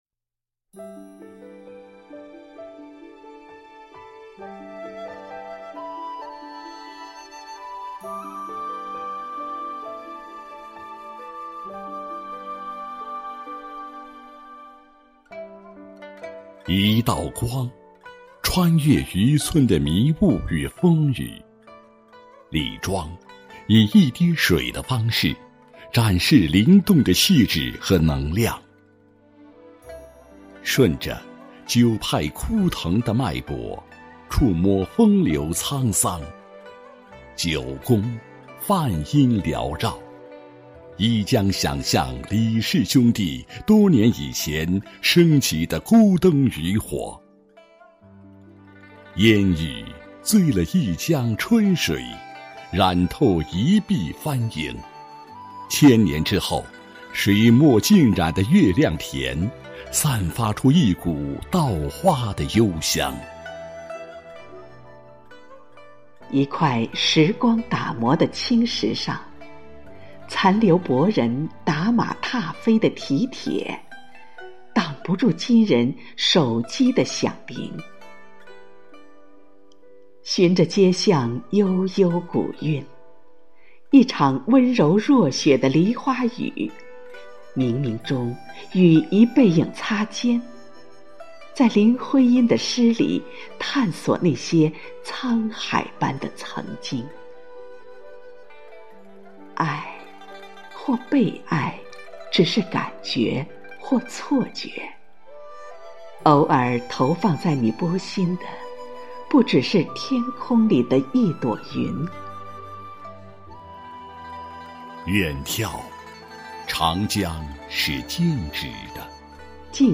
誦讀